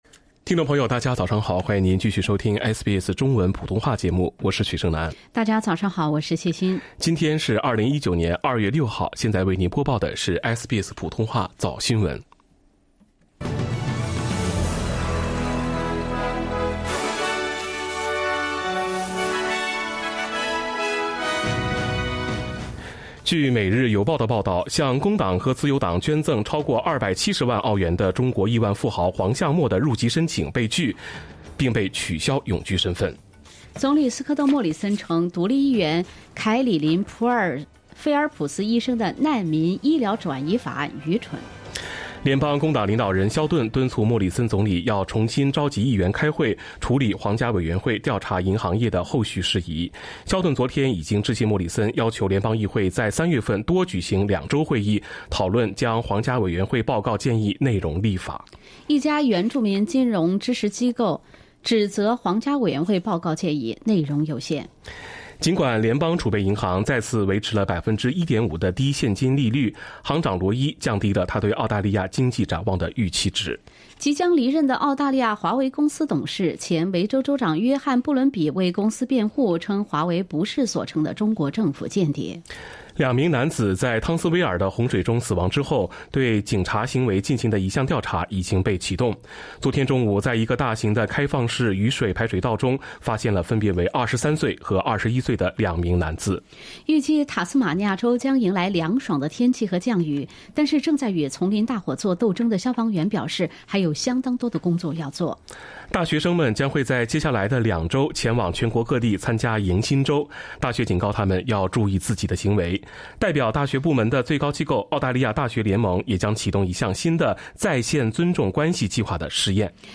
SBS Chinese Morning News Source: Shutterstock
mandarin_morning_news_feb_6.mp3